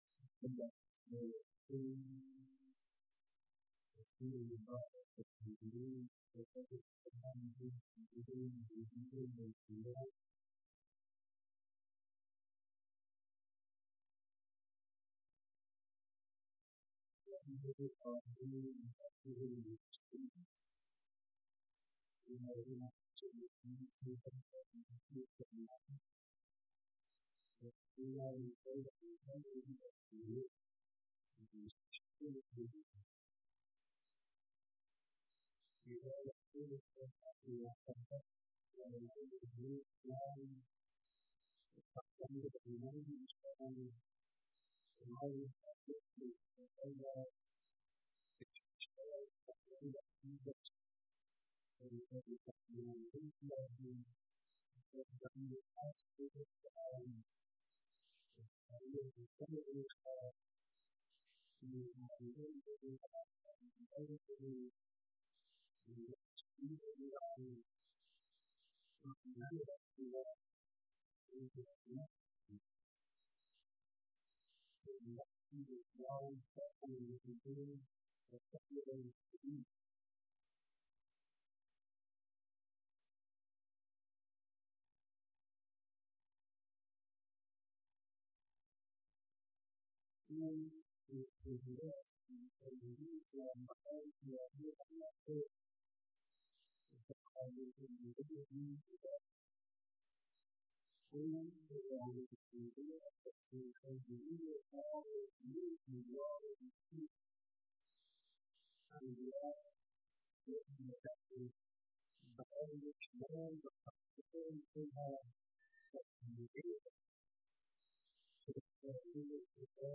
صوت/ سخنرانی احمدی نژاد در دیدار با رهبری